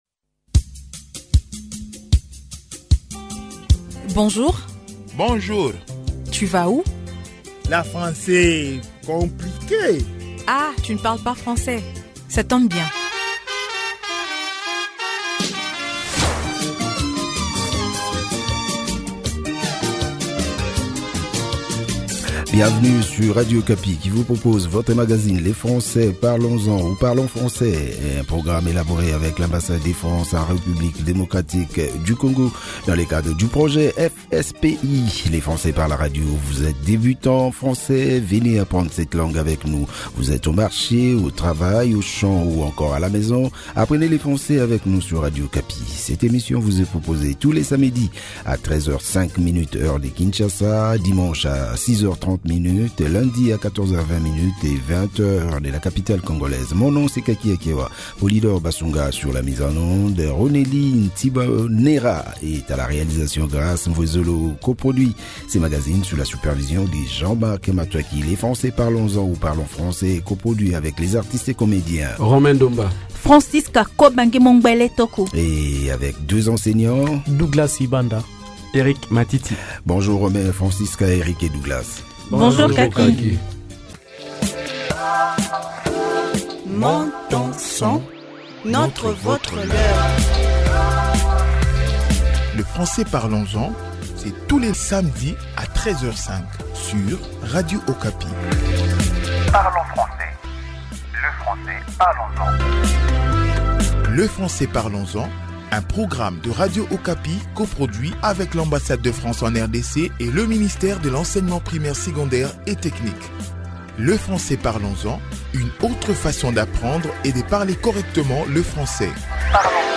À travers ce programme éducatif axé sur le français fonctionnel, nous vous proposons quelques formules et expressions courantes et pratiques.